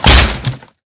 zombiedeath.ogg